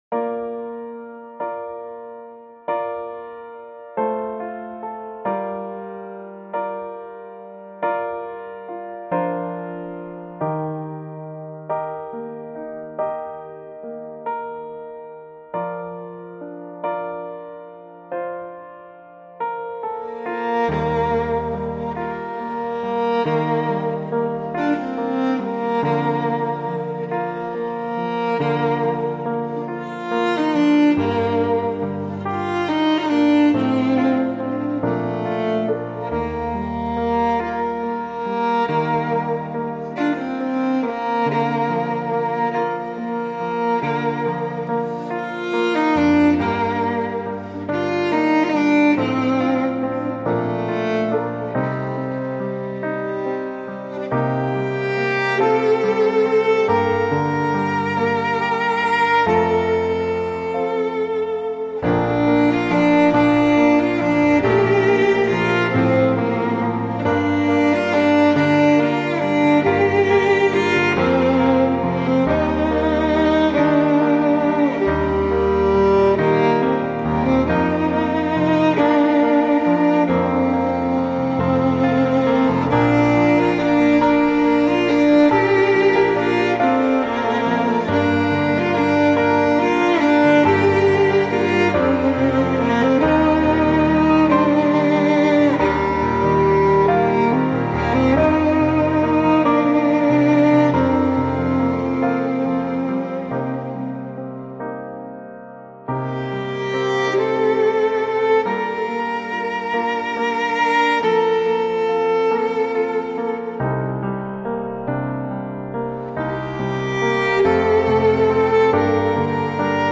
romantic_bg.mp3